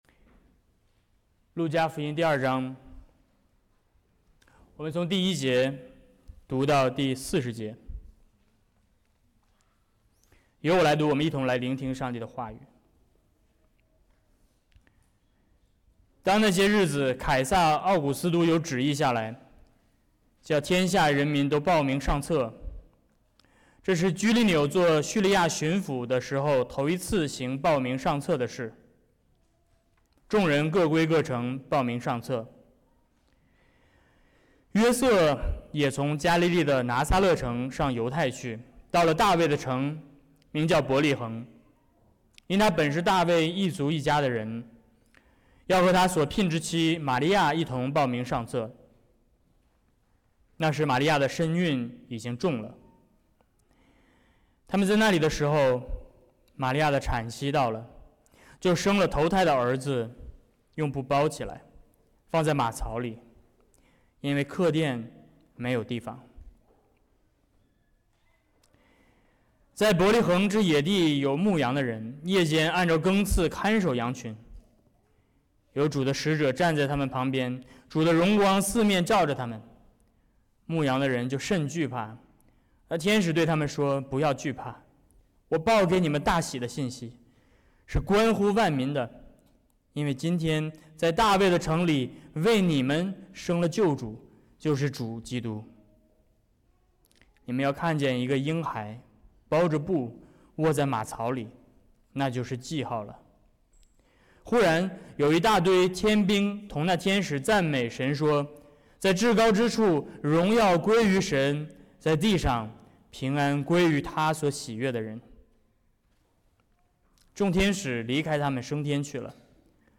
2025年降临期 Service Type: Sermons 2025年12月14日 | 主日50 圣道宣讲： 路加福音2:1–40 | 喜乐的源泉 « 多特信经｜52五项教义